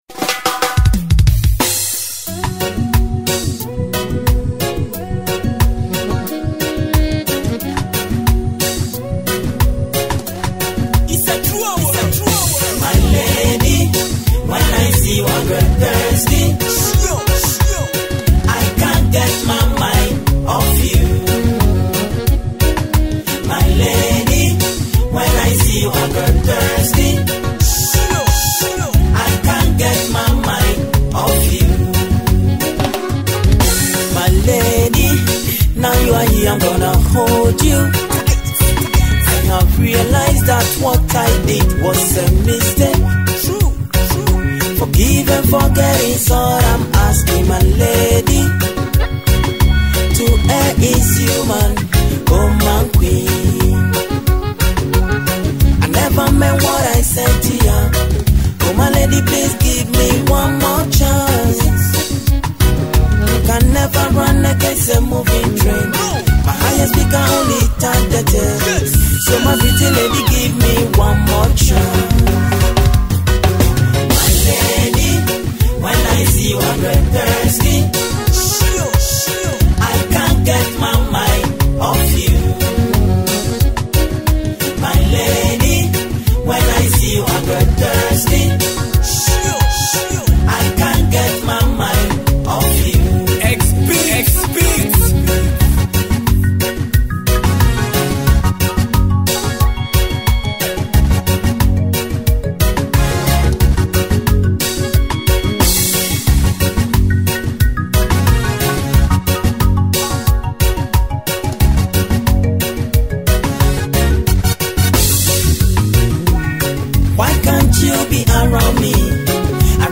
Ghanaian highlife
emotional love tracks